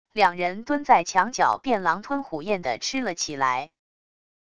两人蹲在墙角便狼吞虎咽地吃了起来wav音频生成系统WAV Audio Player